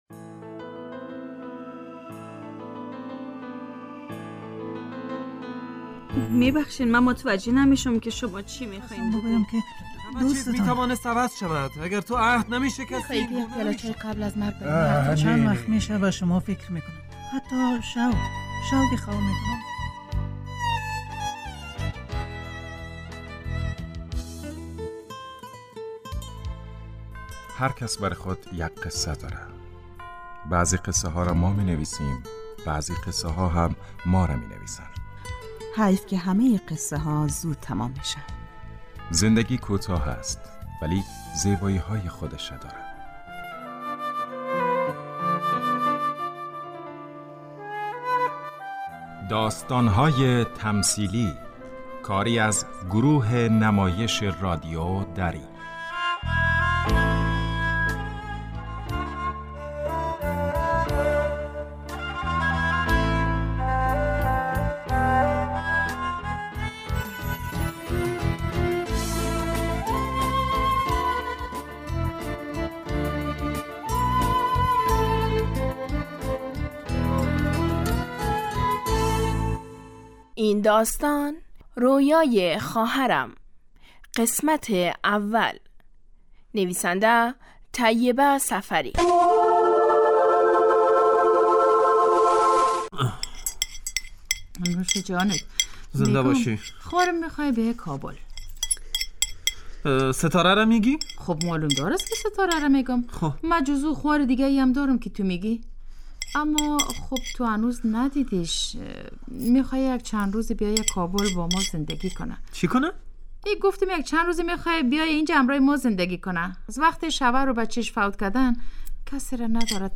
داستان تمثیلی
داستان های تمثیلی هر روز ساعت شانزده و سی و پنج دقیقه از رادیو دری پخش می شوند رویای خواهرم یک داستان پنج قسمتی جذاب است در این داستان با سرشکستگی و نا امیدی مواجه می شویم اما تلاش همیشه در زندگی حرف اول را می زند